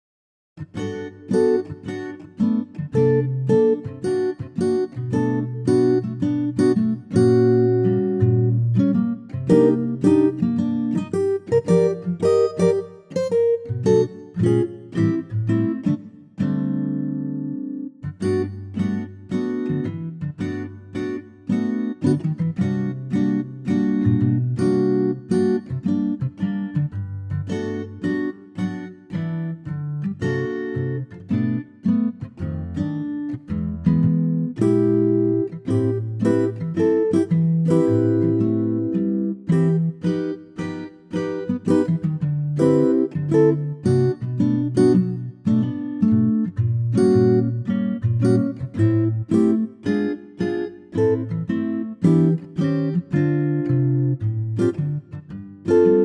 key - G - vocal range - Bb to E